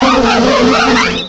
pokeemerald / sound / direct_sound_samples / cries / vespiquen.aif
-Replaced the Gen. 1 to 3 cries with BW2 rips.